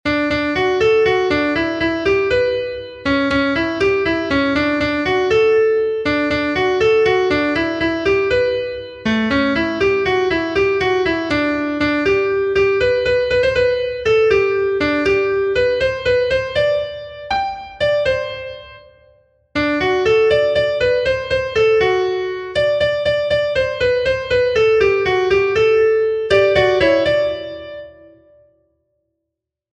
Irrizkoa
A-B-A-C-D-